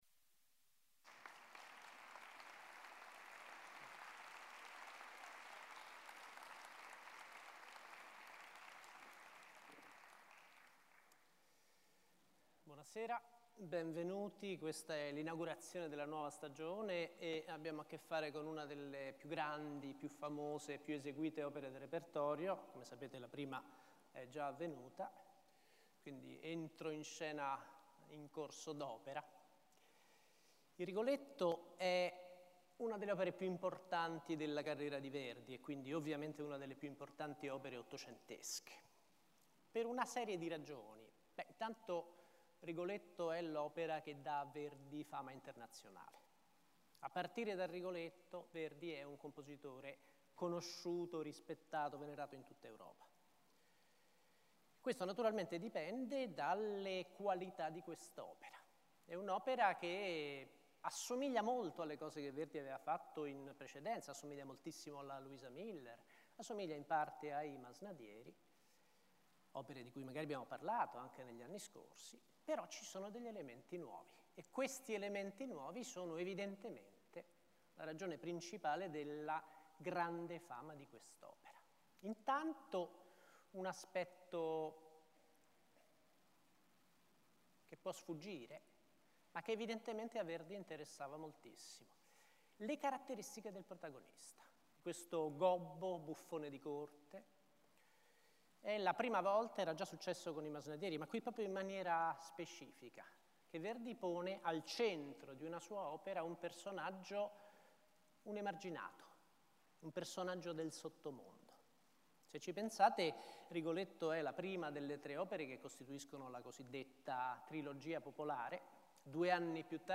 Ascolta la lezione dedicata a Rigoletto